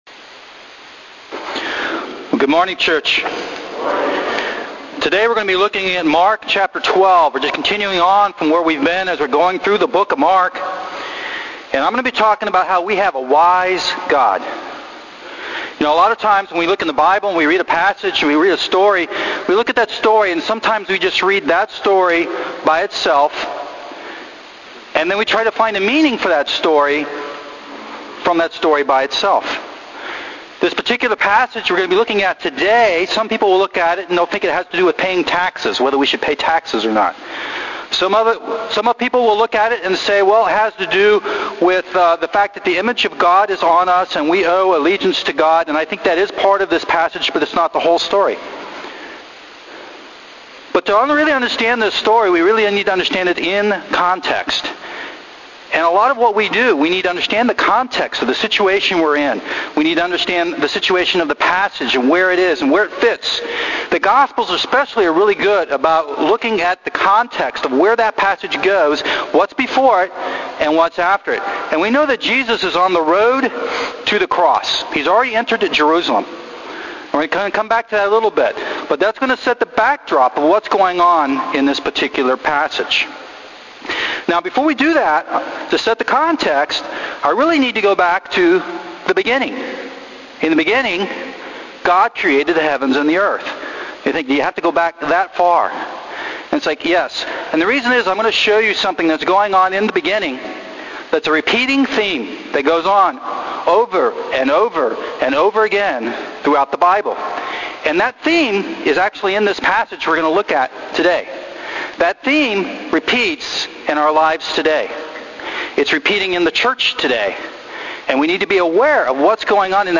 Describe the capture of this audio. This is a sermon I gave at G3 Fellowship on April 28, 2013 on Mark 12:13-17. It goes beyond the traditional message about the tribute to Caesar and shows the importance of literary as well as historical context in understanding the meaning of a passage, especially in the gospel accounts.